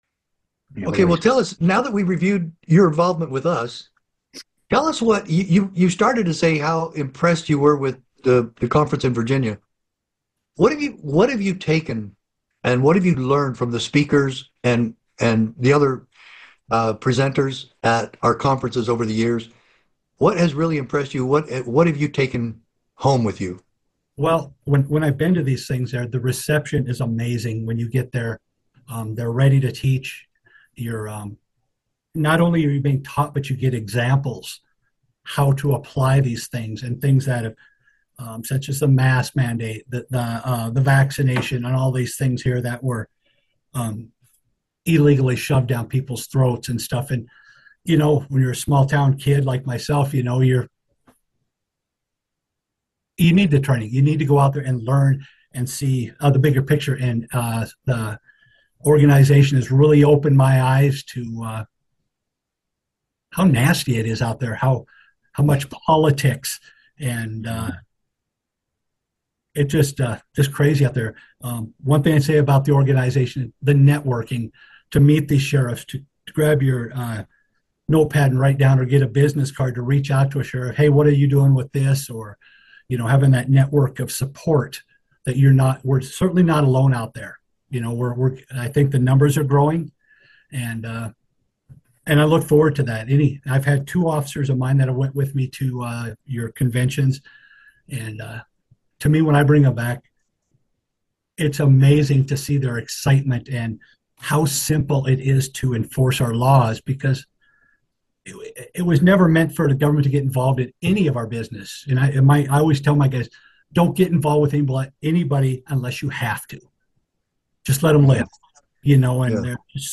The speaker also discusses his role as a sheriff in a sparsely populated area, where his duties often involve helping the community rather than dealing with crime.